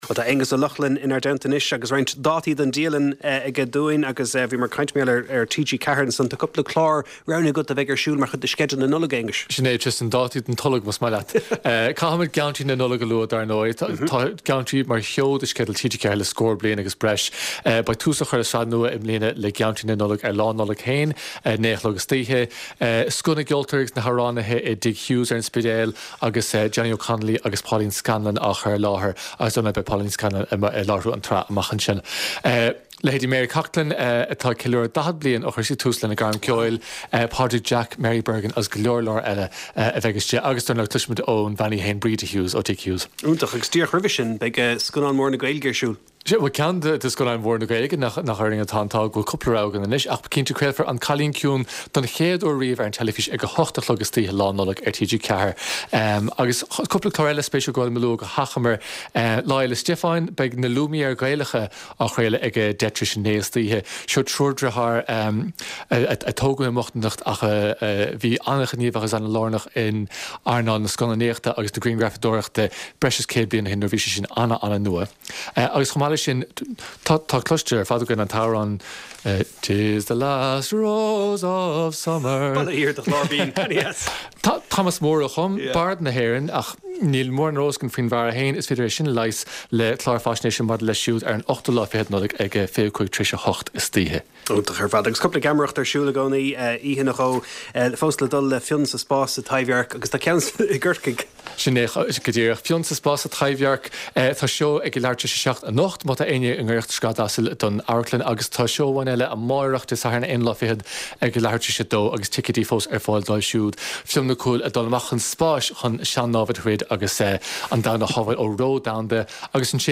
Clár cúrsaí reatha an tráthnóna á chur i láthair ón Lárionad Raidió i mBaile Átha Cliath. Scéalta náisiúnta agus idirnáisiúnta a bhíonn faoi chaibidil ar an gclár, le plé, anailís agus tuairiscí.